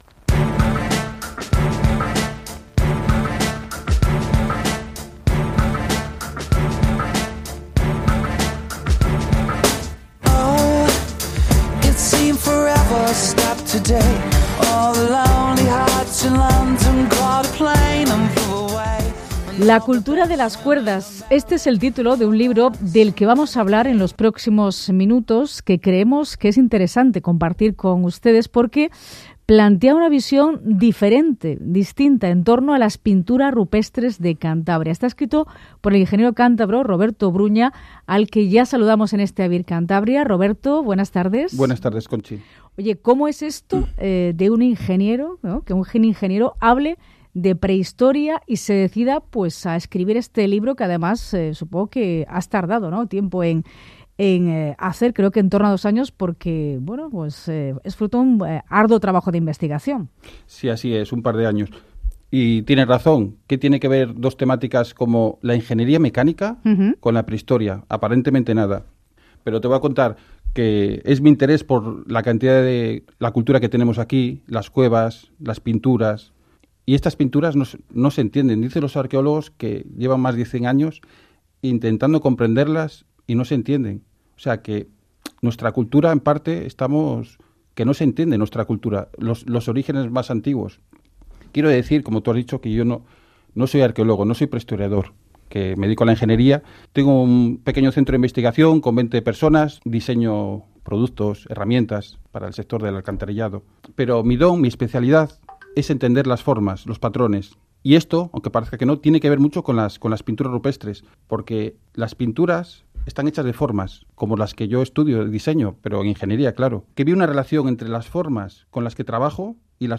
Escucha mi entrevista en la SER